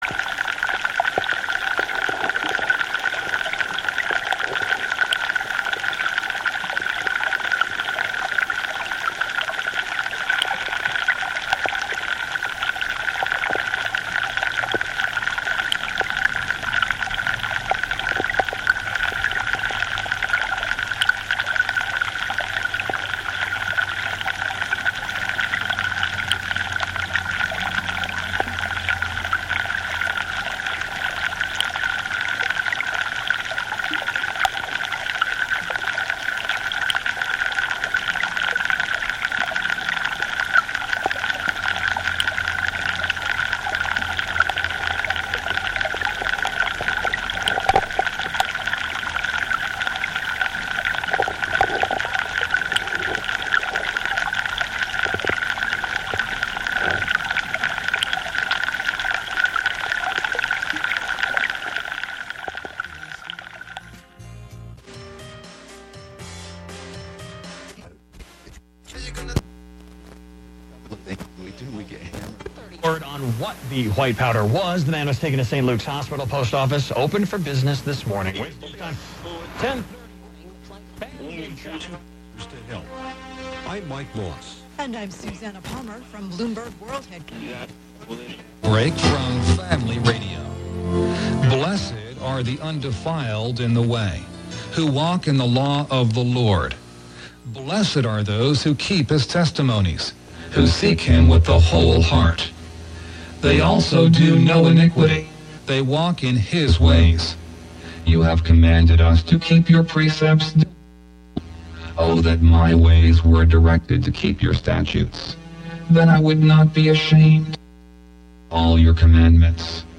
11am Live from Brooklyn, New York
instant techno
play those S's, T's and K's like a drum machine